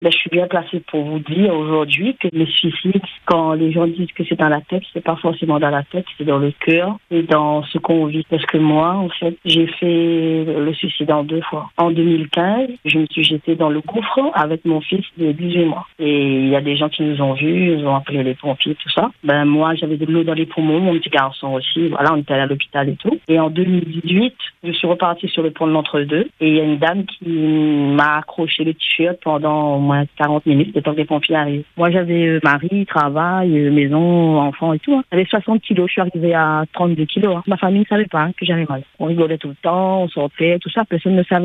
Cette femme raconte avoir traversé l’une des périodes les plus sombres de sa vie, marquée par deux tentatives de suicide.